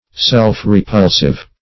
Self-repulsive \Self`-re*pul"sive\, a.